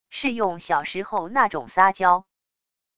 是用小时候那种撒娇wav音频